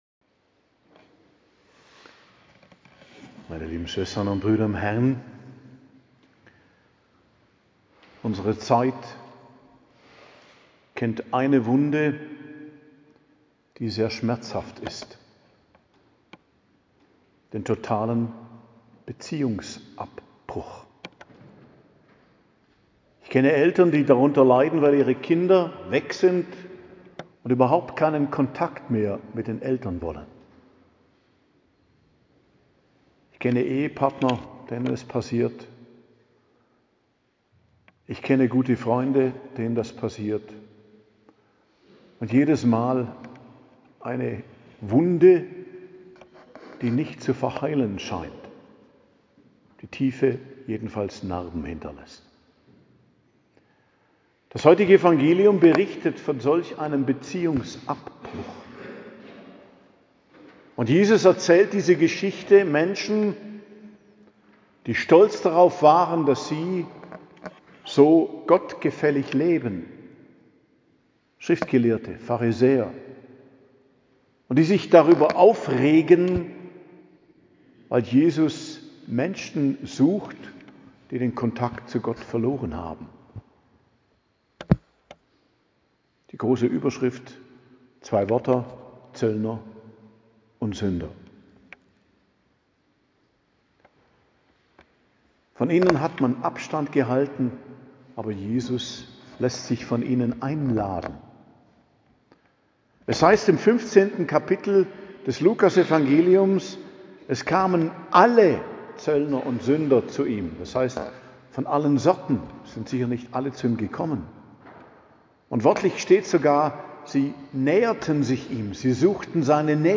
Predigt zum 4. Fastensonntag am 30.03.2025 ~ Geistliches Zentrum Kloster Heiligkreuztal Podcast